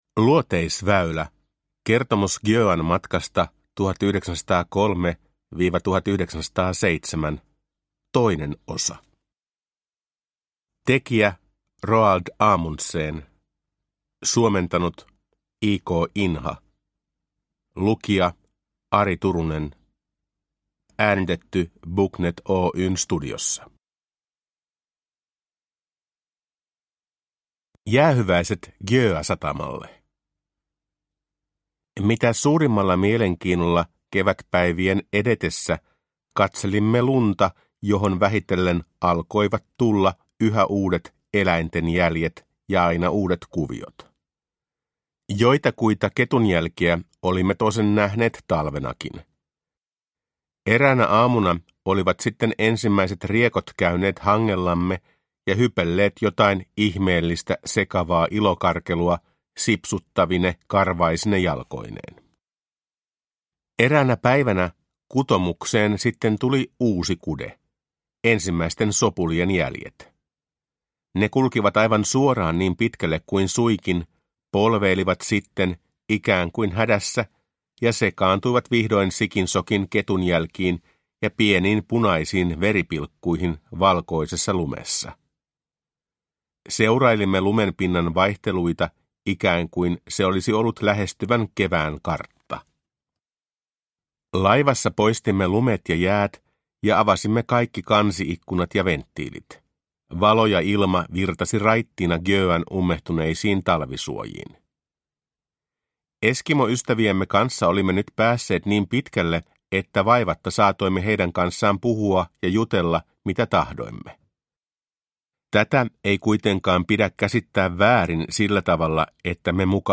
Luoteisväylä – Ljudbok